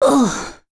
Pavel-Vox_Damage_01.wav